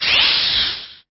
081-Monster03.mp3